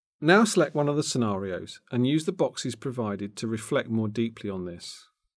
Narration audio (OGG)